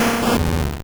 Cri de Limagma dans Pokémon Or et Argent.